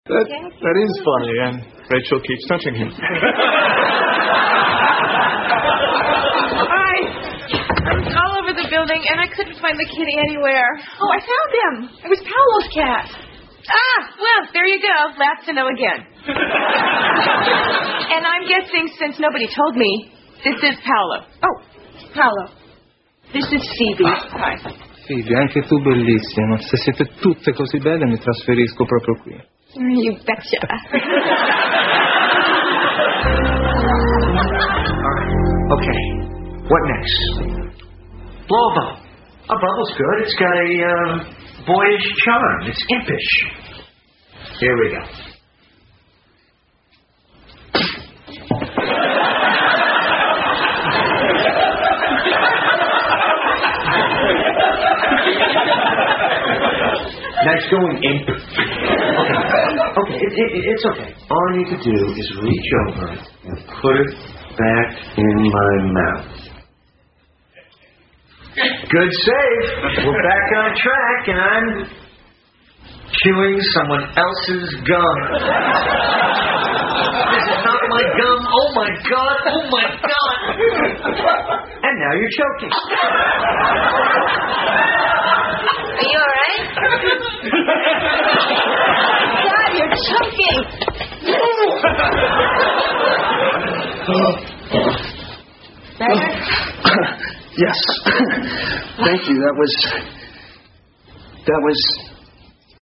在线英语听力室老友记精校版第1季 第84期:停电(11)的听力文件下载, 《老友记精校版》是美国乃至全世界最受欢迎的情景喜剧，一共拍摄了10季，以其幽默的对白和与现实生活的贴近吸引了无数的观众，精校版栏目搭配高音质音频与同步双语字幕，是练习提升英语听力水平，积累英语知识的好帮手。